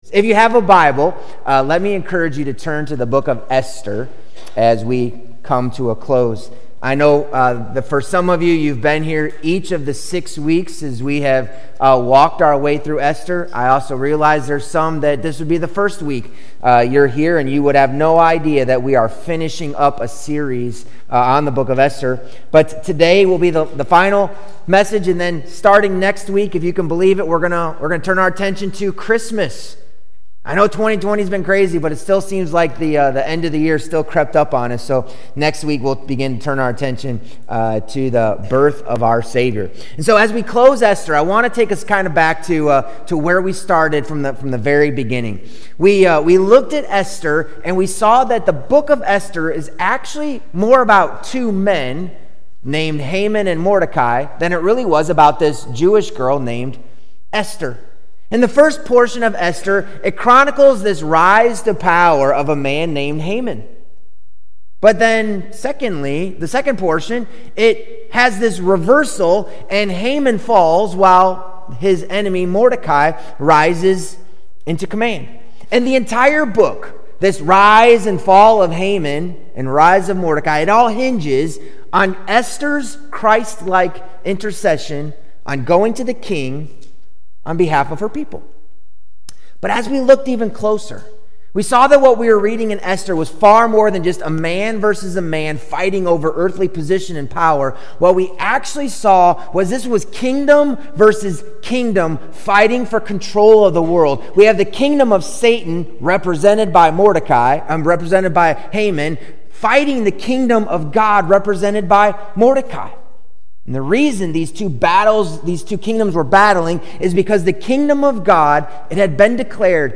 Sermons | Mt. Carmel Regular Baptist Church